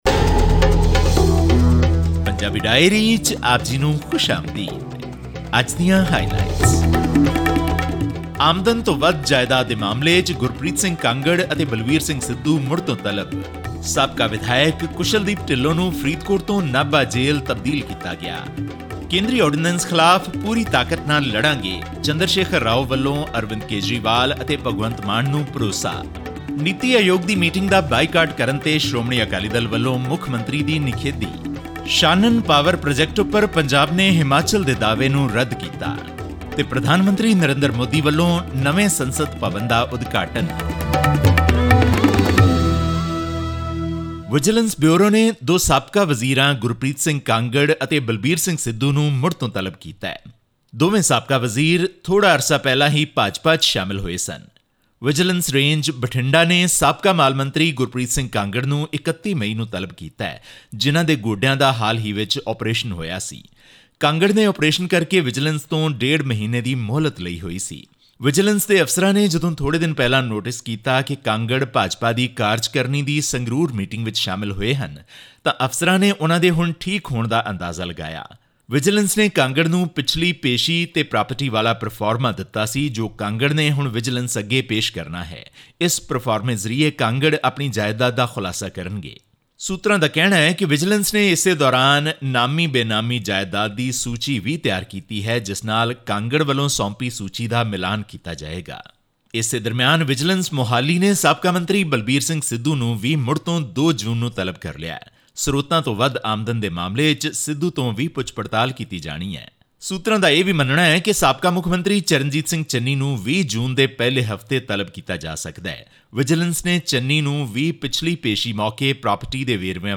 ਇਸ ਖ਼ਬਰ ਅਤੇ ਪੰਜਾਬ ਨਾਲ ਸਬੰਧਤ ਹੋਰ ਖ਼ਬਰਾਂ ਦੇ ਵੇਰਵੇ ਜਾਣਨ ਲਈ ਸੁਣੋ ਪੰਜਾਬੀ ਡਾਇਰੀ ਦੀ ਰਿਪੋਰਟ।